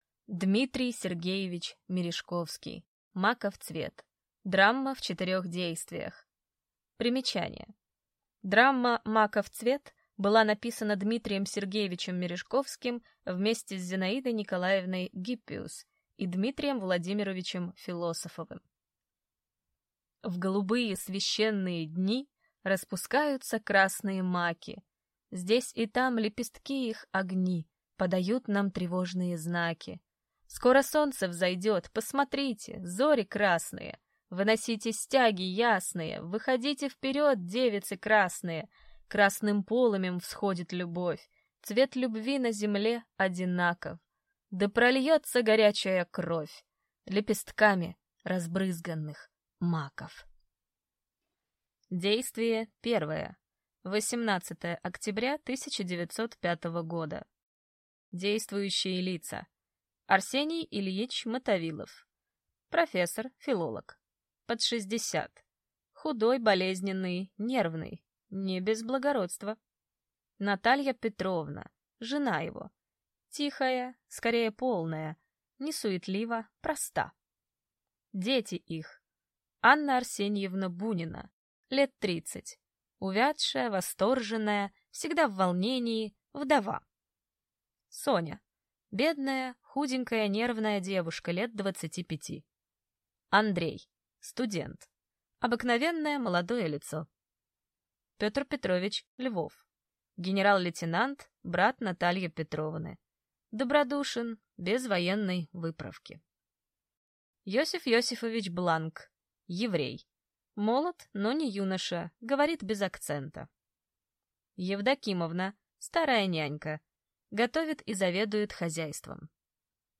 Аудиокнига Маков цвет (драма в 4-х действиях) | Библиотека аудиокниг